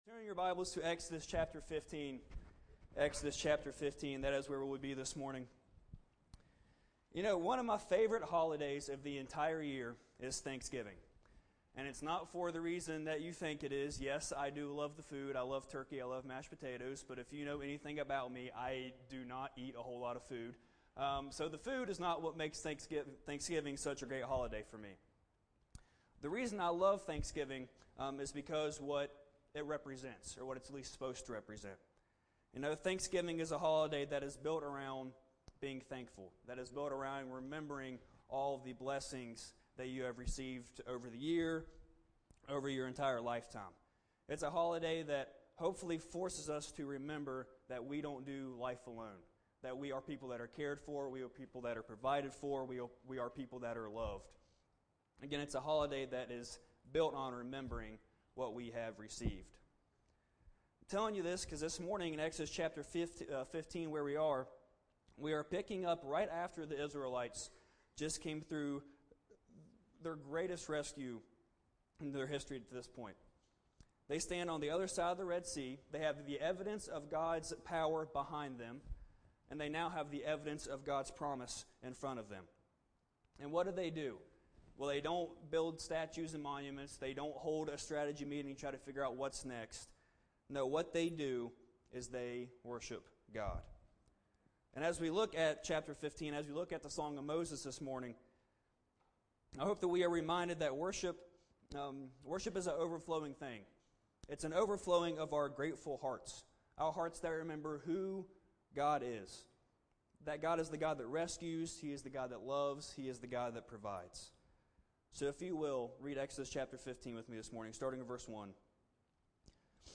Chapel Messages